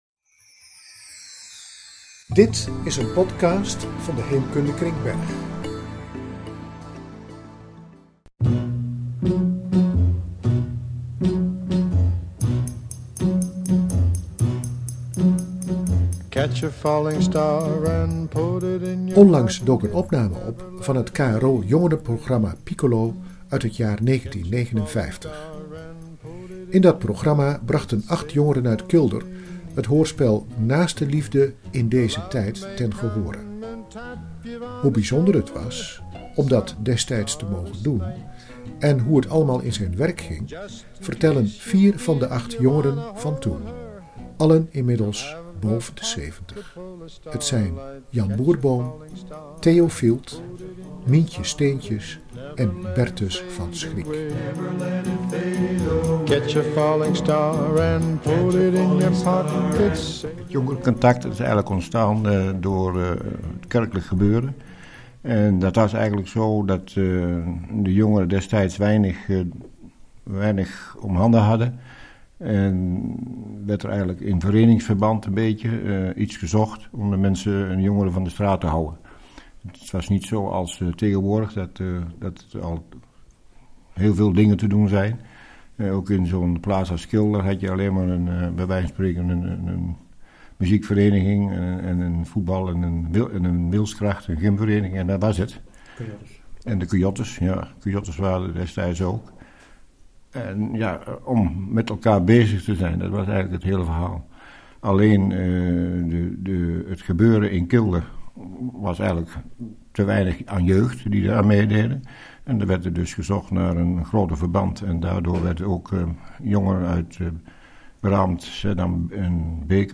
Vier 'jongeren' van toen halen 52 jaar later herinneringen op.
Interview
podcast-39b-jongerencontact-interview.mp3